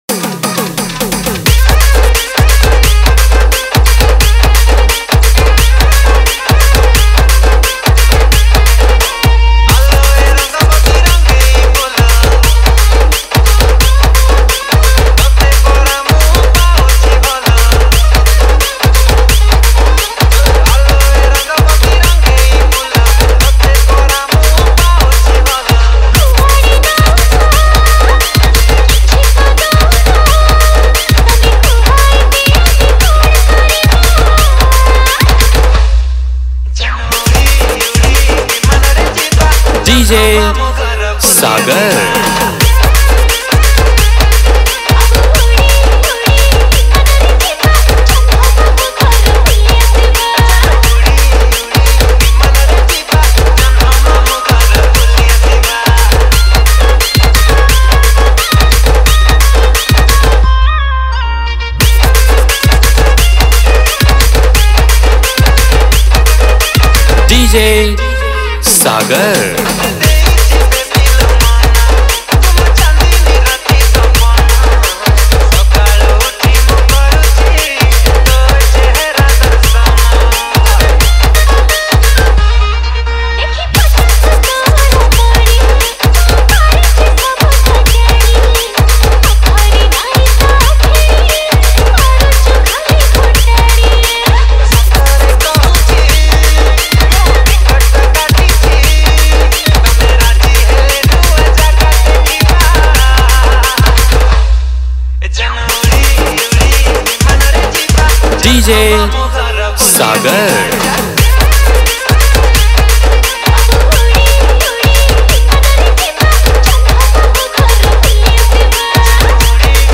Category:  New Odia Dj Song 2025